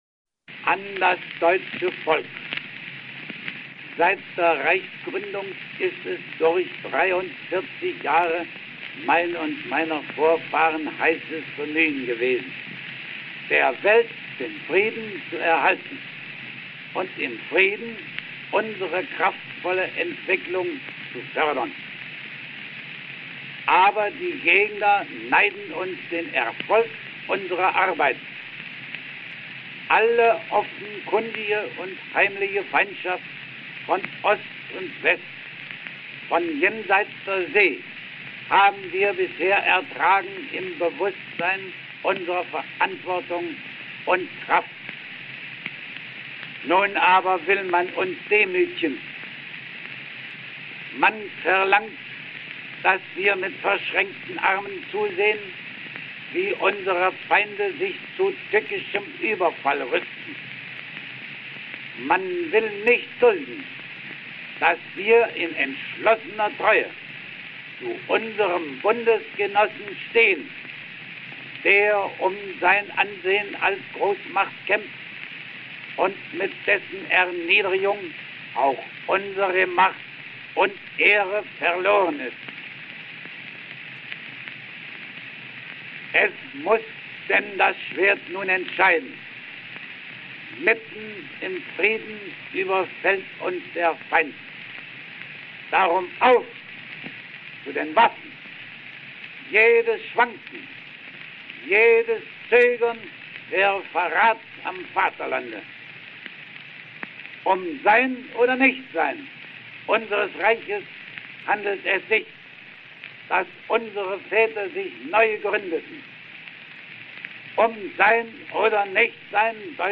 Вильгельм II /Friedrich Wilhelm von Preußen An das deutsche Volk (см. текст), 6 авг. 1914 Берлин